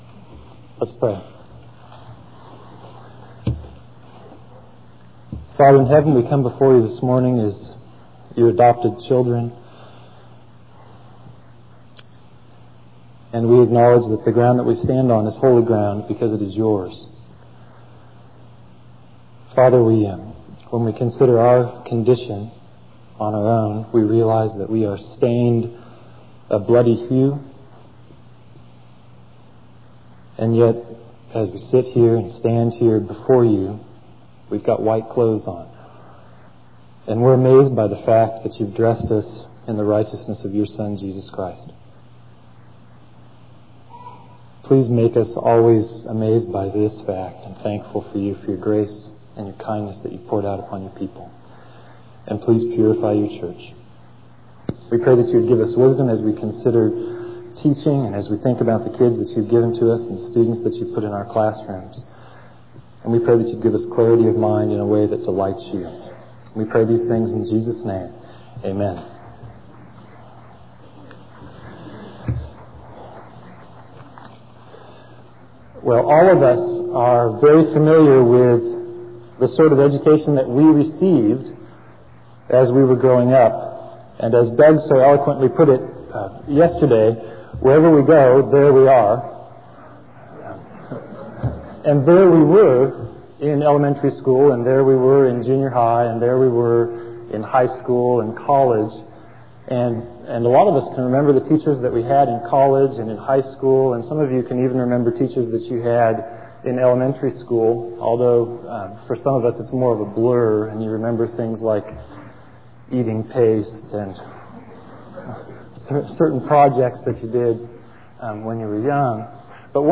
2002 Workshop Talk | 0:46:40 | All Grade Levels, Virtue, Character, Discipline